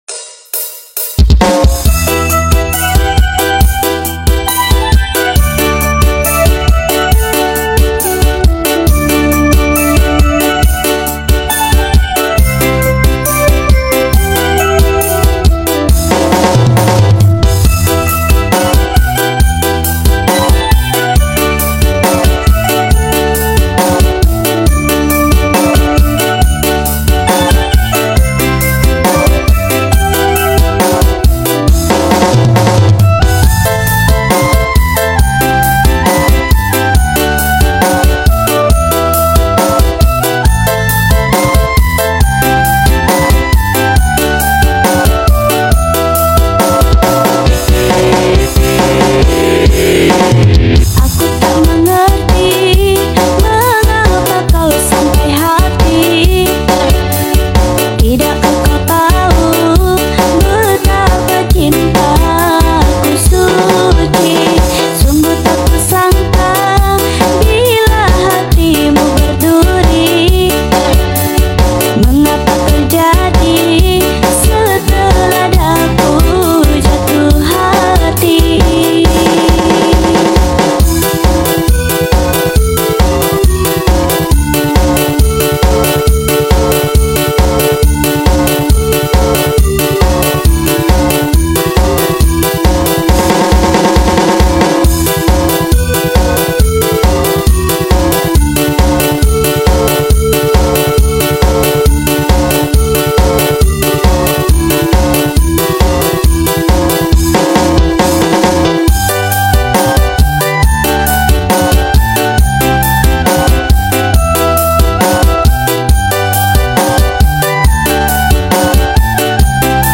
DANGDUT REMIX SLOW FULL BASS